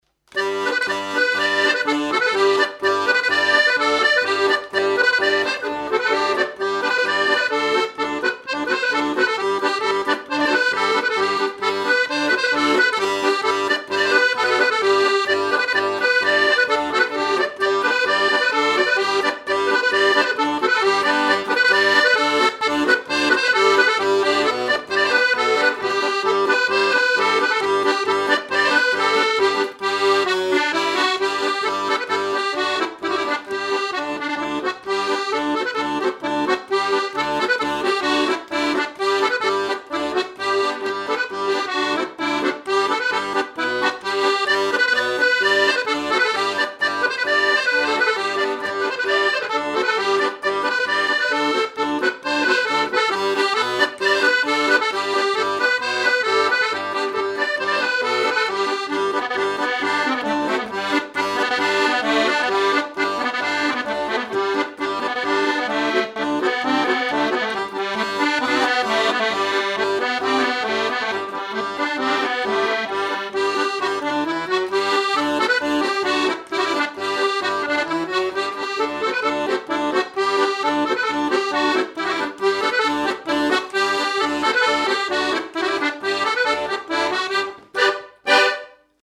Boulogne
danse : branle : courante, maraîchine
Pièce musicale inédite